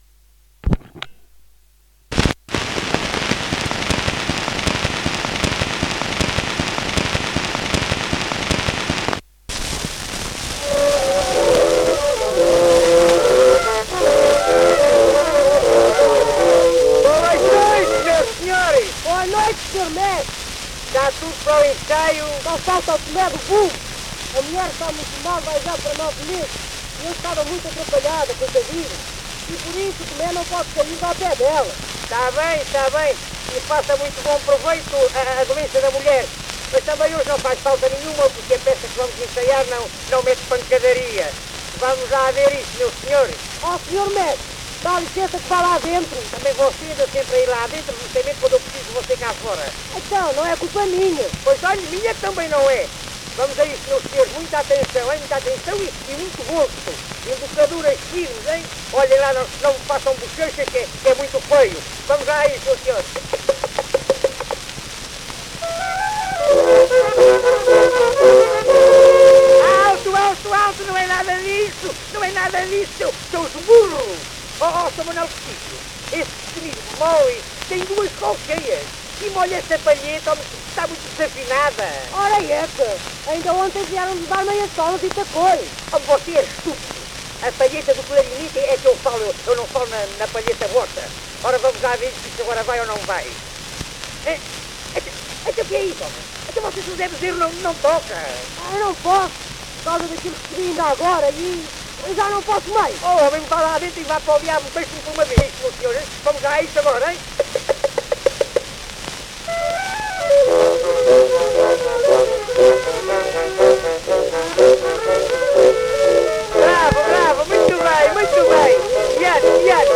inetmd-fcsh-ifpxx-mntd-audio-ensaio_duma_filarmonica-641.mp3